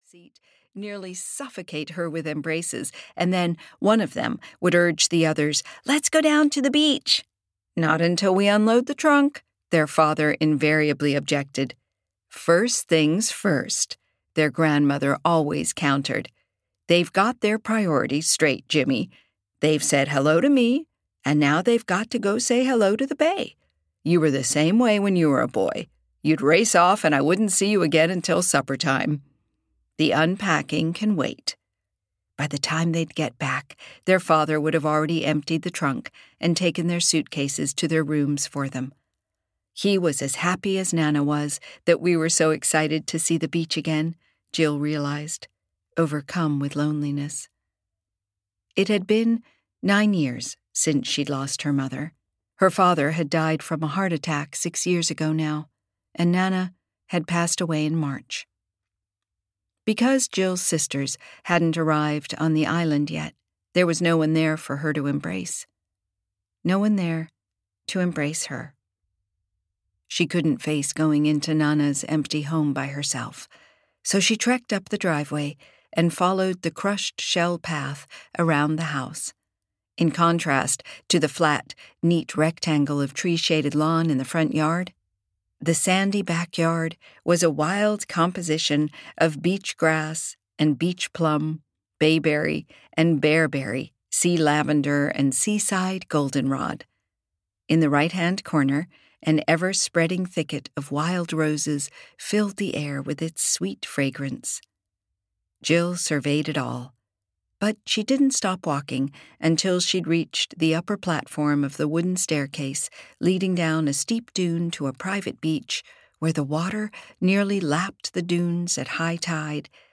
A Letter from Nana Rose (EN) audiokniha
Ukázka z knihy